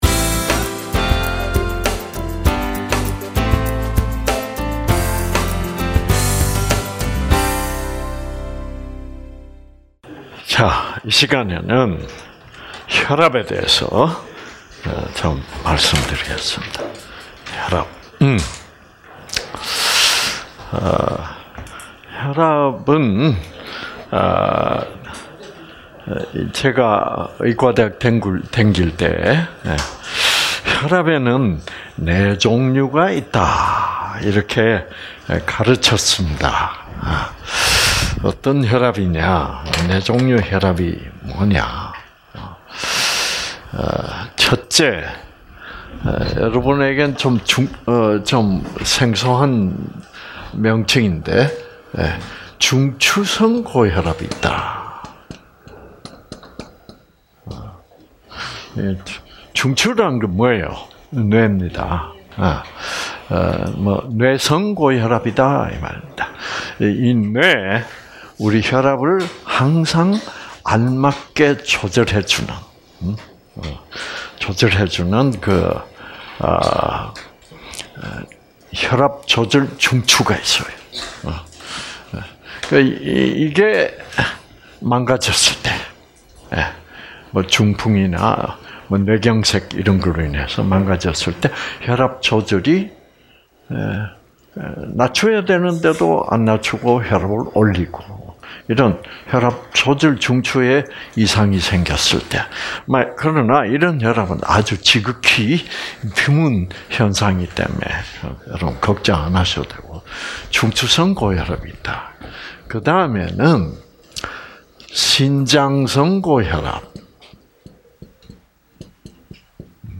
세미나 동영상 - 제242기 1부 프로그램 (2020.7.5~7.14)